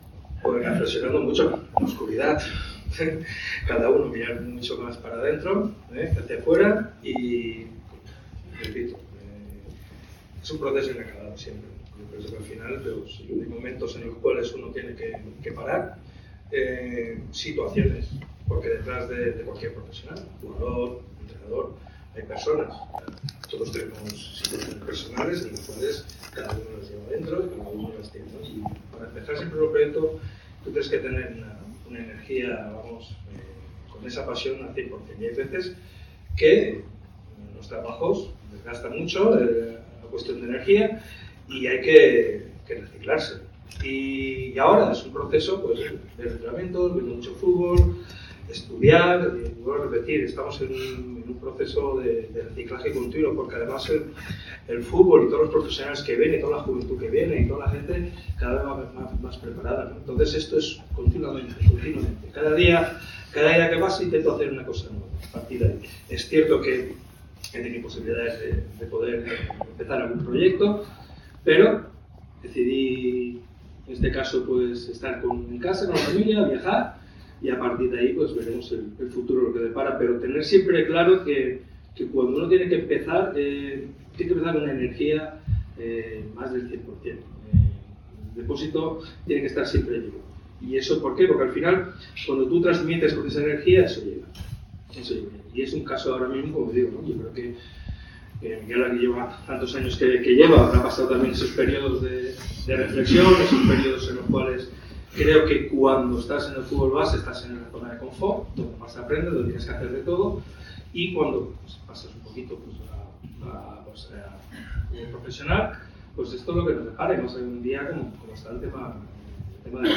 La mesa redonda “De la base al profesionalismo” se celebró este miércoles a las 19:00 horas y participaron